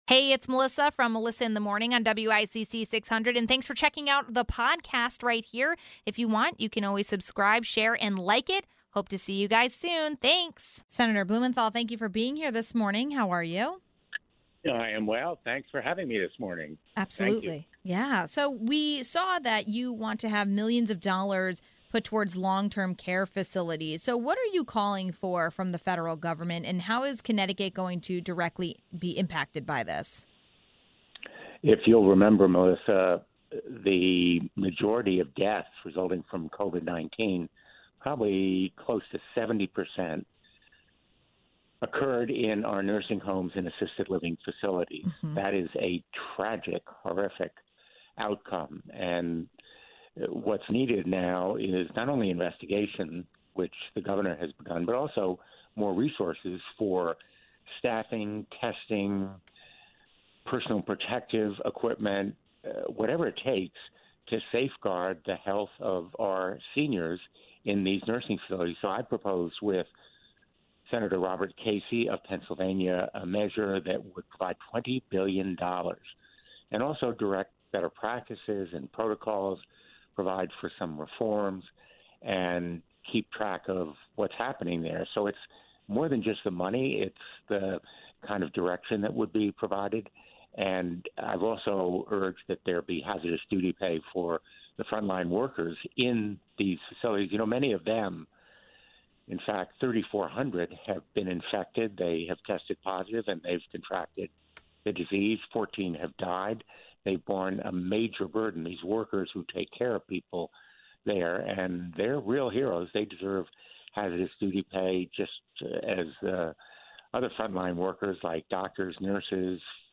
1. Senator Richard Blumenthal talked about millions potentially going to nursing homes.
((00:10)) 2. Shelton Mayor Mark Lauretti talks about the possibility of the Columbus statue from Bridgeport coming to Shelton. ((07:12)) 3. The BBB talk about employment scams.